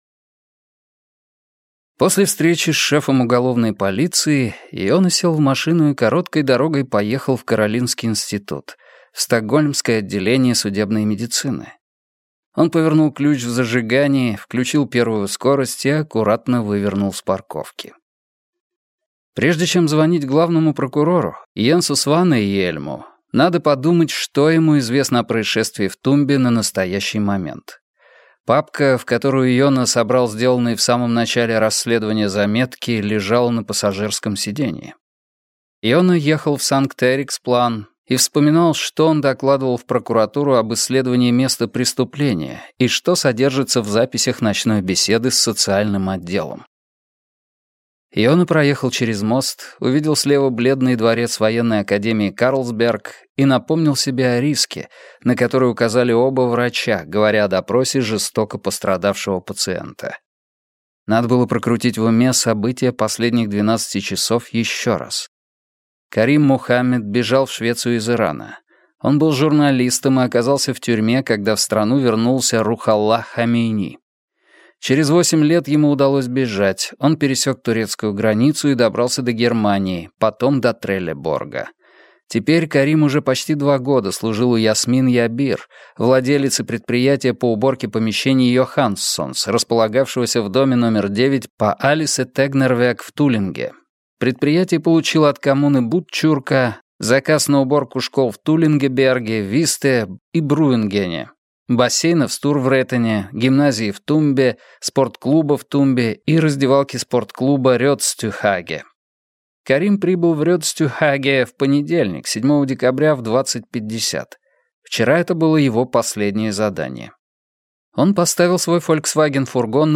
Аудиокнига Гипнотизер | Библиотека аудиокниг